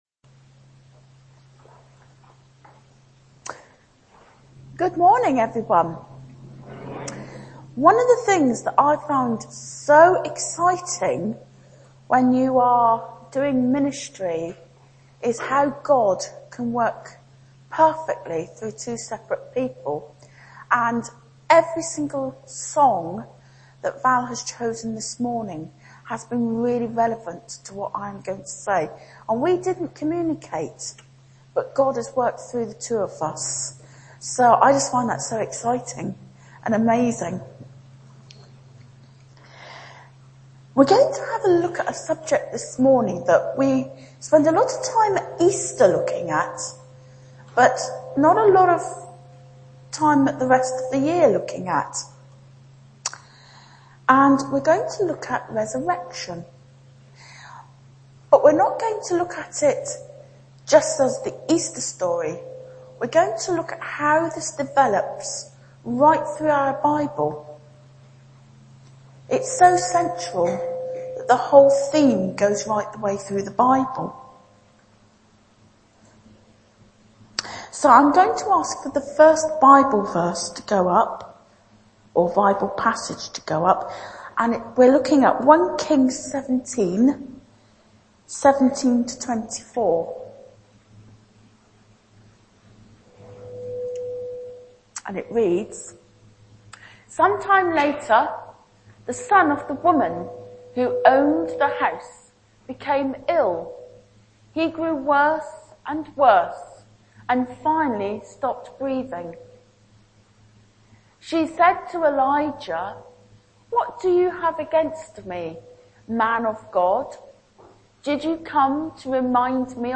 Bible Text: 1 Ki 17:17-24, Jn 11:38-44, Lk 24:13-16, Rev 21:1-4 | Preacher